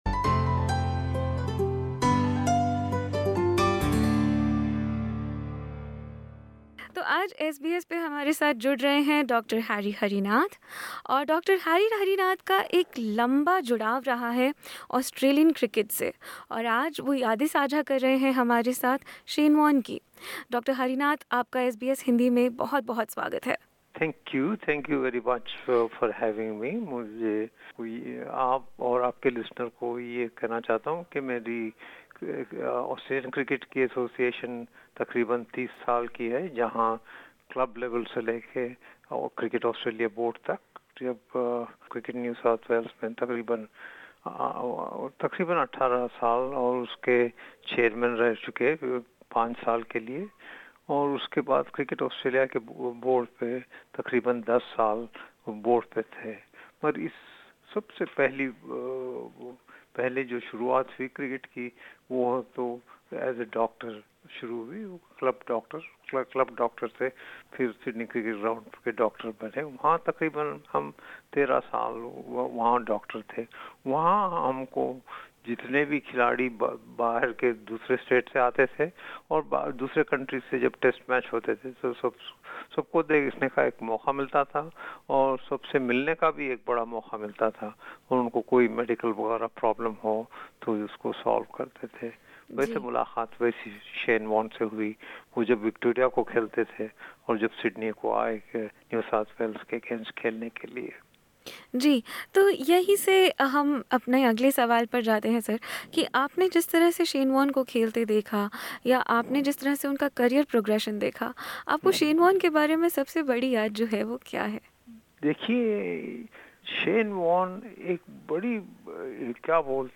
कैसे उनकी फिरकी देखने के लिए सिडनी के ग्राउंड भर जाया करते थे, या कैसे खाने-पीने के शौक़ीन होते हुए भी, वॉर्न की फिटनेस हमेशा खेल के अनुरूप रही, या कैसे टीम के साथ खेलने और बढ़ने की उनमें एक अनूठी लगन थी- ऐसी ही यादों की बारात है यह साक्षात्कार।